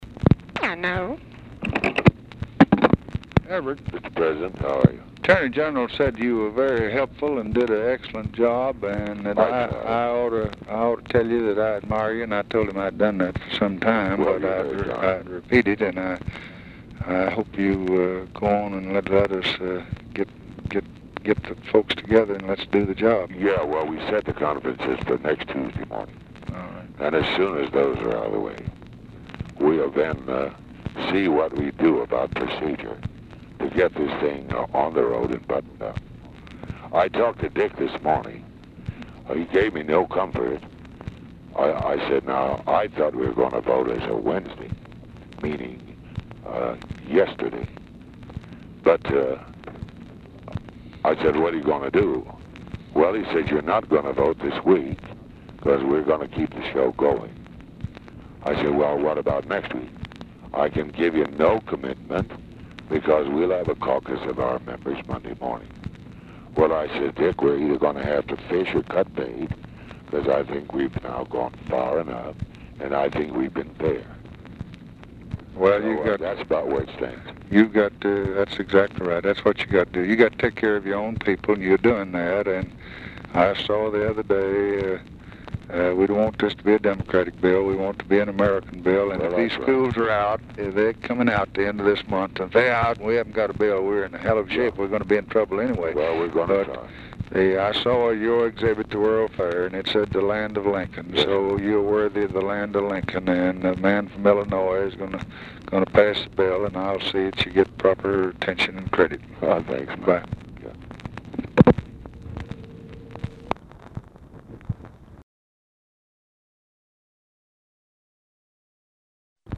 U.S. Senate: Civil Rights Act of 1964 | Cloture & Final Passage: Phone Conversation, May 13, 1964
President Lyndon B. Johnson’s conversation with Senator Everett Dirksen on May 13, 1964.